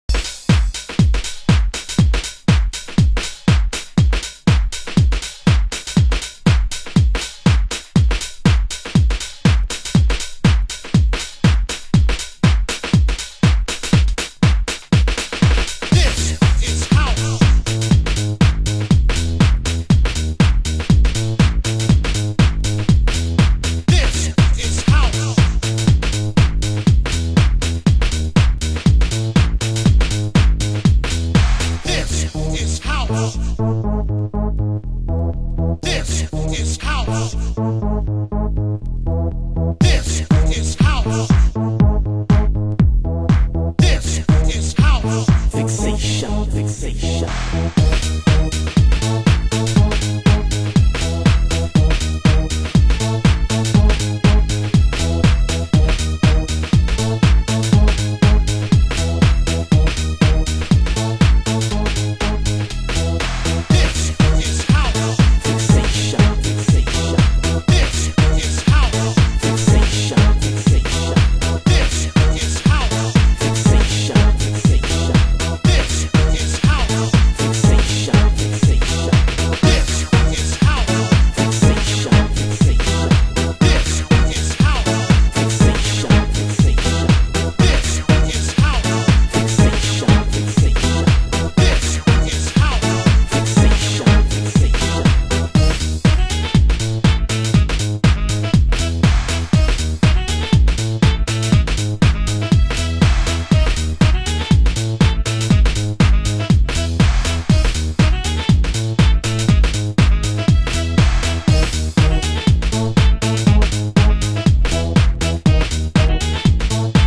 HOUSE MUSIC
盤質：軽いスレ傷/少しチリパチノイズ有